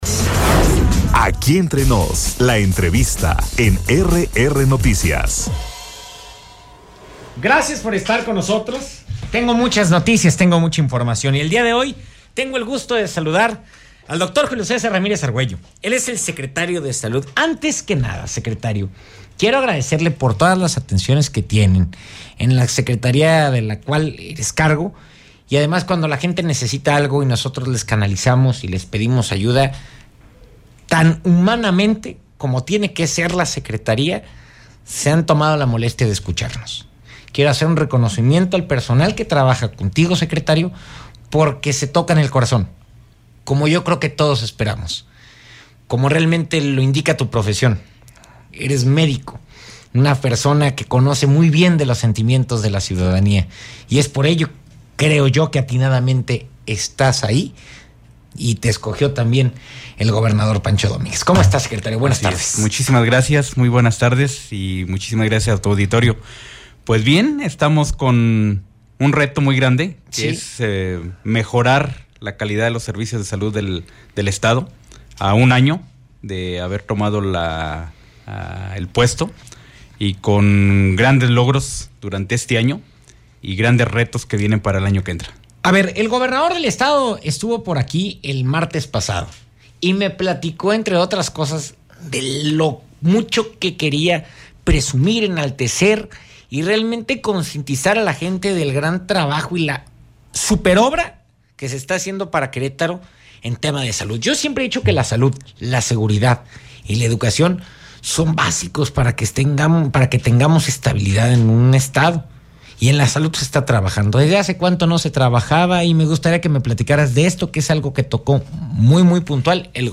En entrevista el secretario de Salud estatal, Julio César Ramírez Argüelles, informó la obra del hospital de Querétaro estaría lista el 30 de diciembre - RR Noticias
ENTREVISTA-SECRETARIO-DE-SALUD-JULIO-CESAR-RAMIREZ-ARGÜELLO.mp3